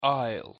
Czyta się mniej więcej tak: ajl.
pronunciation_en_aisle.mp3